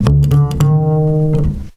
DoubleBass 5 F.wav